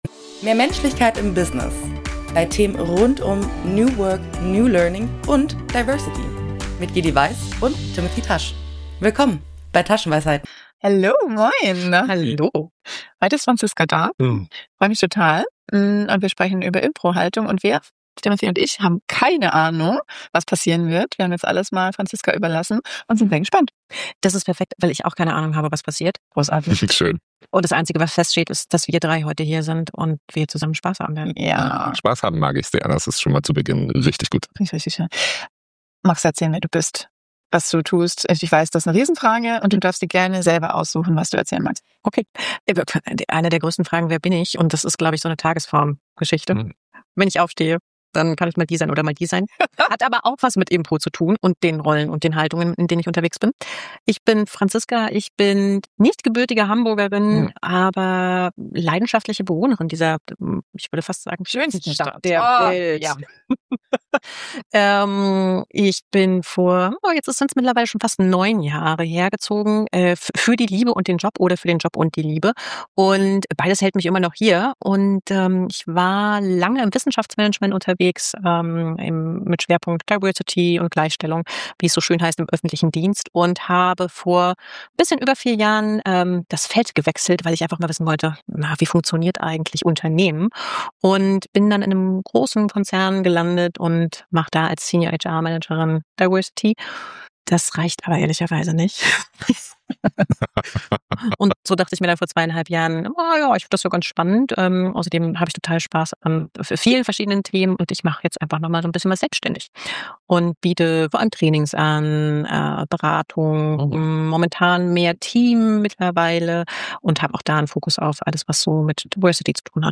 Wir führten ein Gespräch über Neugier, Scheitern, Resonanz, Haltung, Kontrolle und darüber, warum es manchmal die schönste Erkenntnis ist, wenn jemand etwas ganz anderes mitnimmt, als du geplant und vorbereitet hattest.